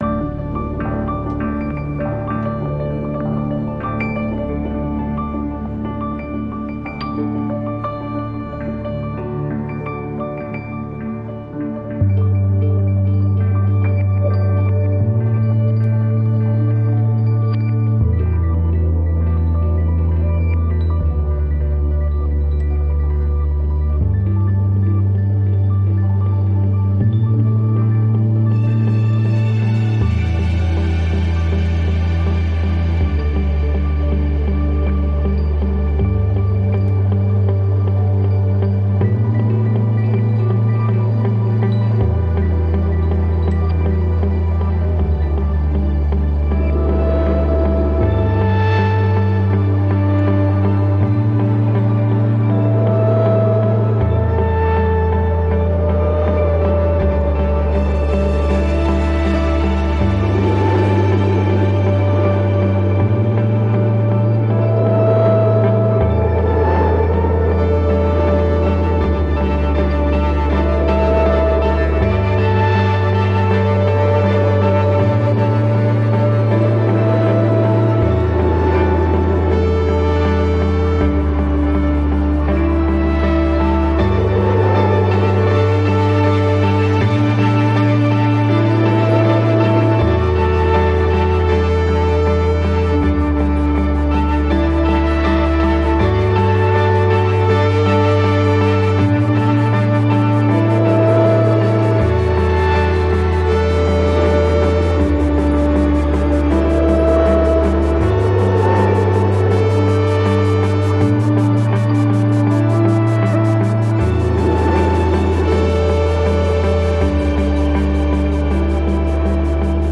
Sound Dust Dust Engine#2 是一款基于 Kontakt 的虚拟合成器，它使用了来自罕见的硬件合成器和现代小工具的混合声音。
- 4 种混合合成器音色，包括 Hammond 102200、DSi Evolver、2 种不同的循环钢琴、循环吉他和塑料喇叭。
- 背景噪音，可以选择唱片噪音或磁带噪音。
- 磁带和胶水控制，可以将有机、肮脏的整体感融入到所有声音中。